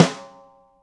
gretsch snare f.wav